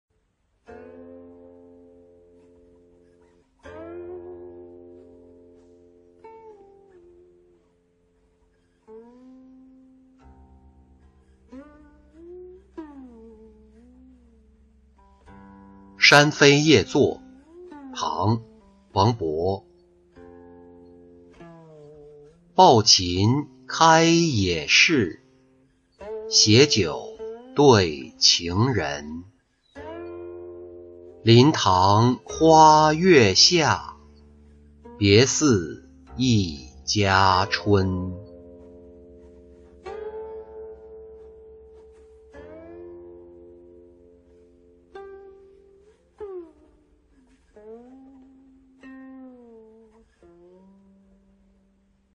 山扉夜坐-音频朗读